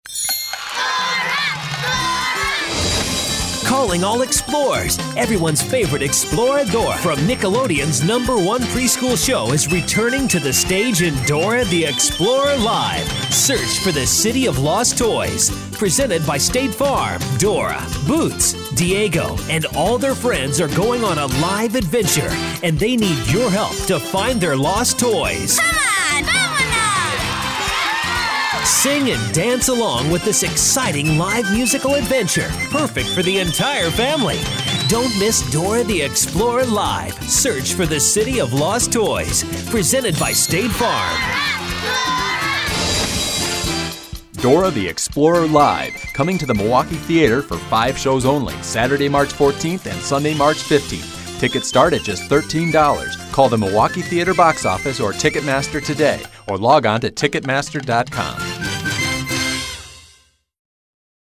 Dora Radio Commercial